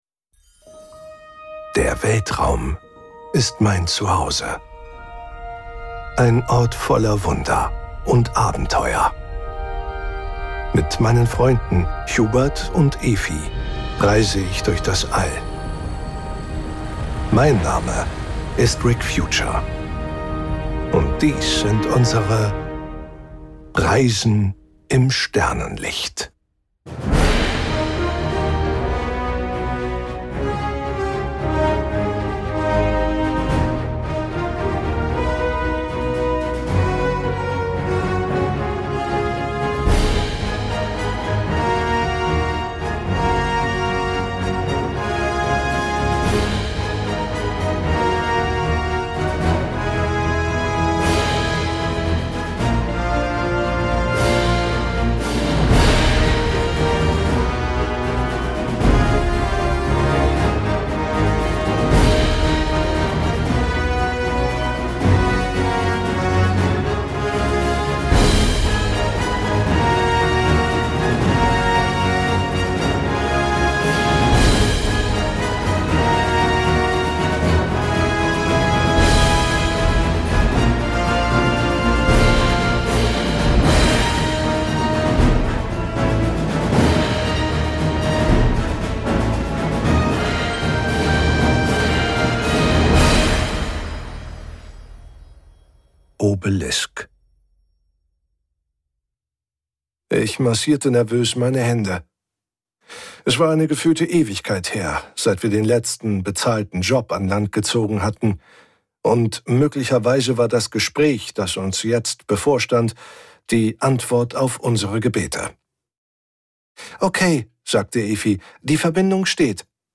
Die fünfte Kurzgeschichte "Obelisk" aus der Sammlung "Reisen im Sternenlicht".